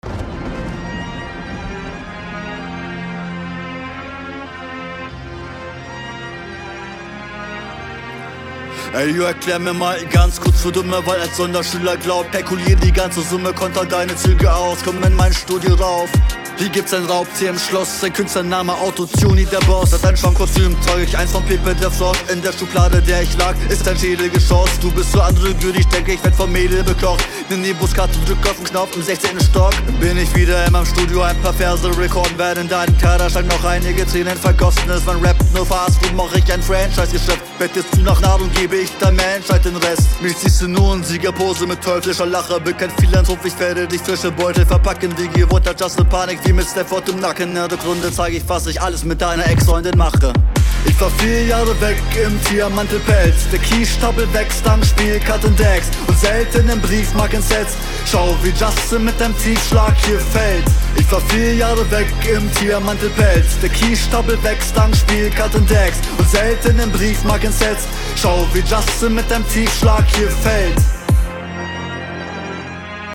Einige Lines leiden unter der Verständlichkeit. Mische klingt gut.
Hast am Anfang einen Verhaspler. Hätte mir hier mehr Stimmeinsatz gewünnscht.